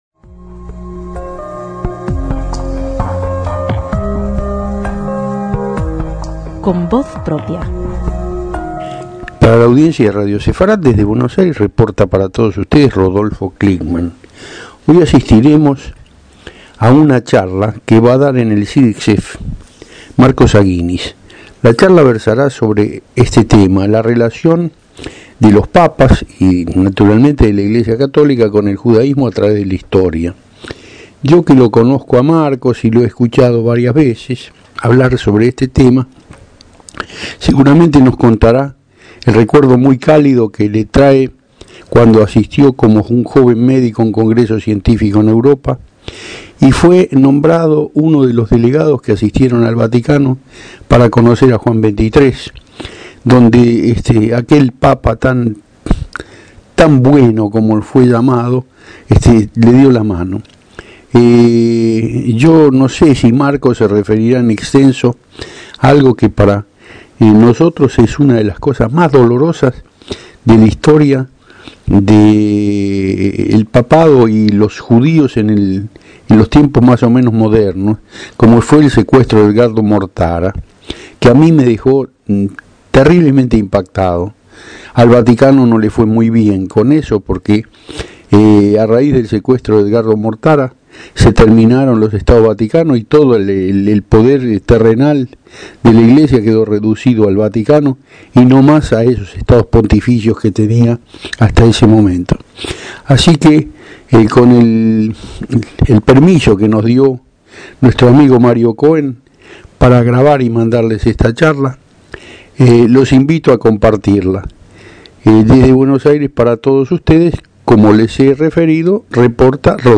Ante gran cantidad de público, que abarrotó el salón de la conferencia